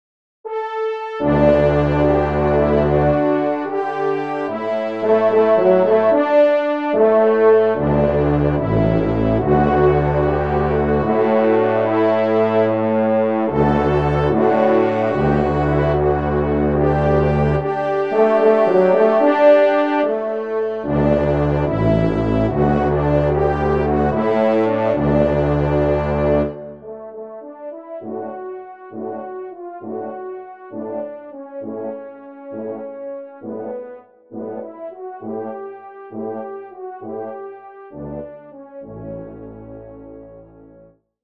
Genre : Divertissement pour Trompes ou Cors
Pupitre 6° Cor